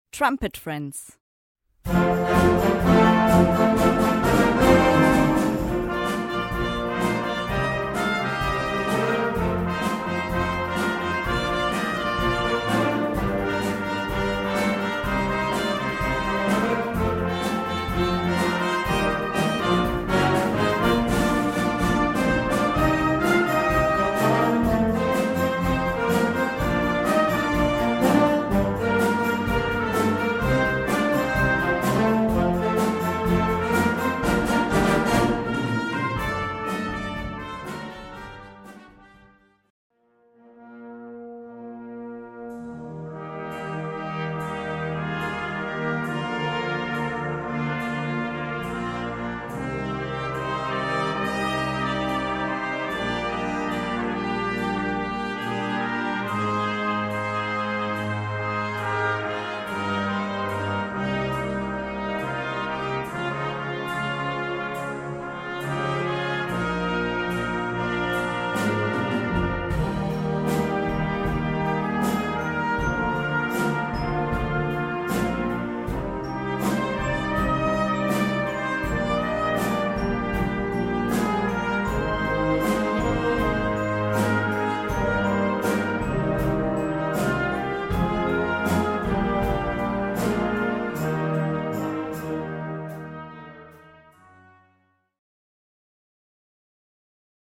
Gattung: Solo für 2 Trompeten und Blasorchester
Besetzung: Blasorchester